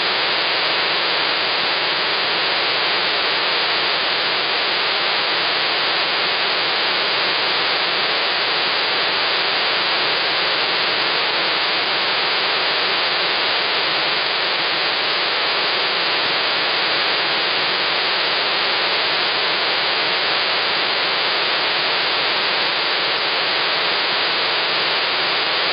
HD_Radio_AM.mp3